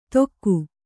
♪ tokku